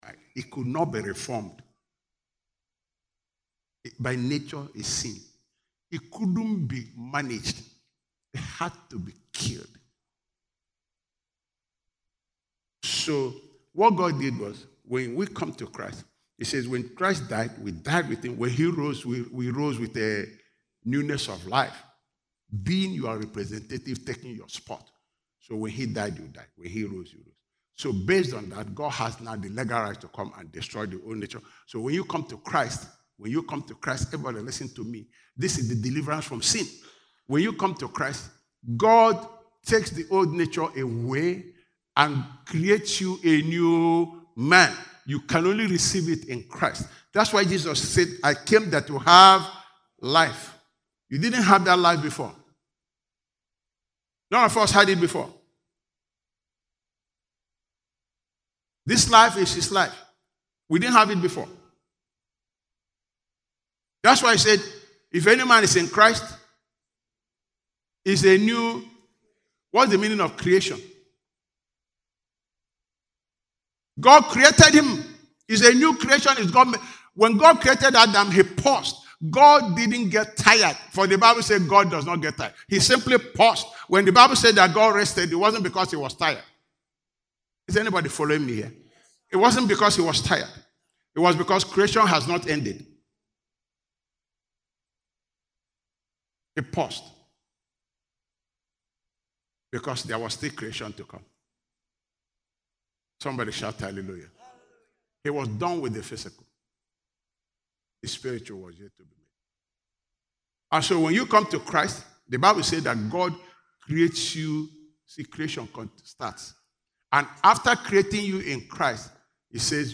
Living Word Conference